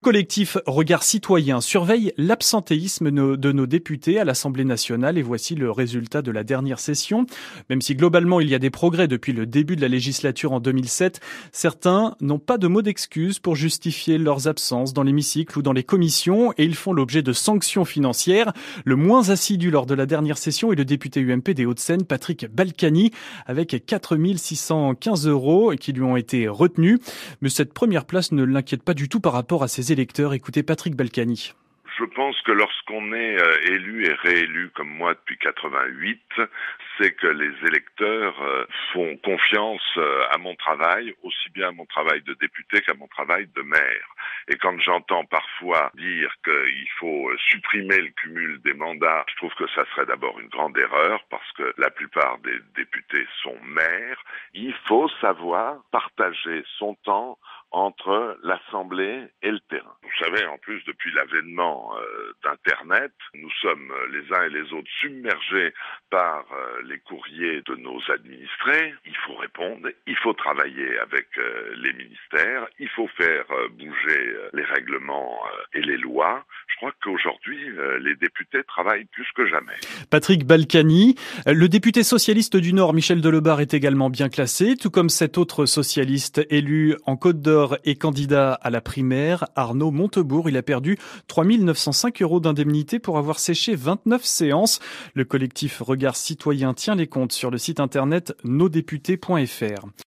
France-Inter consacrait dans son journal du midi du 3 aout 2011 un sujet à l’étude de Regards Citoyens sur les sanctions encourues par les députés à l’Assemblée nationale.